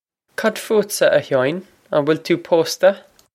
Pronunciation for how to say
Cod foo-t-sa a Haw-in? On wil too poh-sta?
This is an approximate phonetic pronunciation of the phrase.
This comes straight from our Bitesize Irish online course of Bitesize lessons.